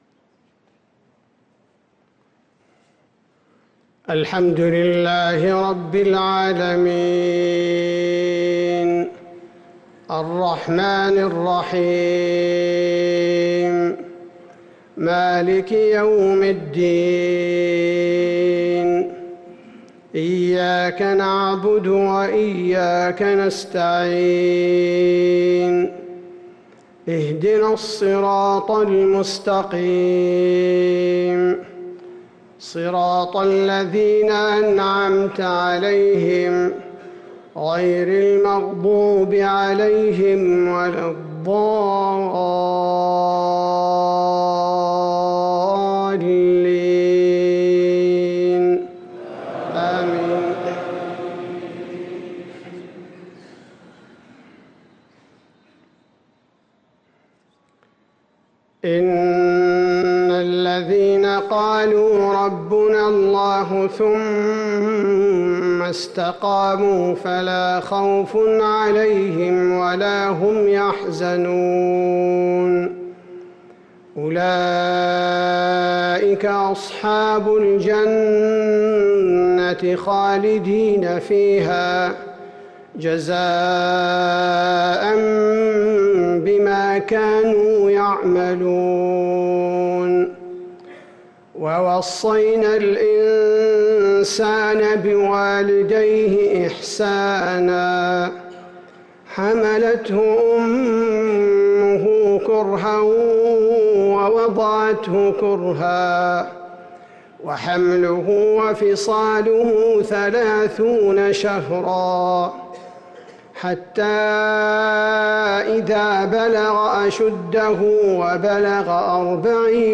صلاة المغرب للقارئ عبدالباري الثبيتي 16 ربيع الآخر 1445 هـ